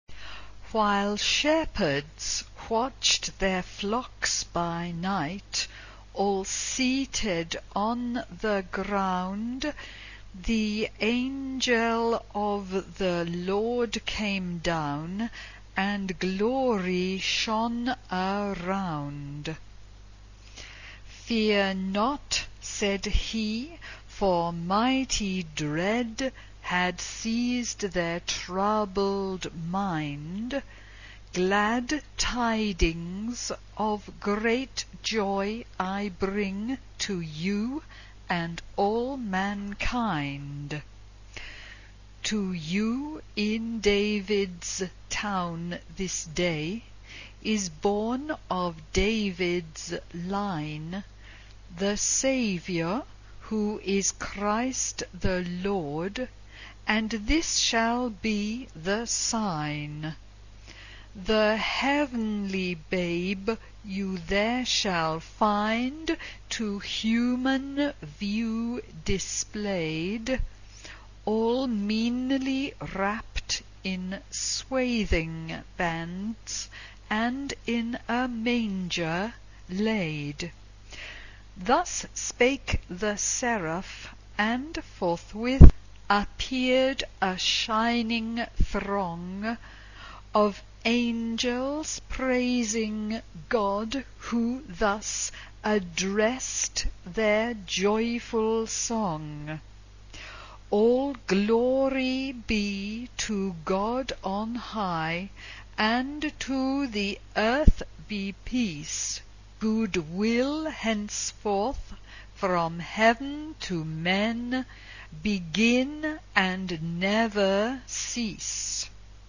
SA (2 voix égale(s) d'enfants) ; Partition complète.
Chant de Noël. Carol.
Caractère de la pièce : joyeux
Instruments : Piano (1)
Tonalité : si bémol majeur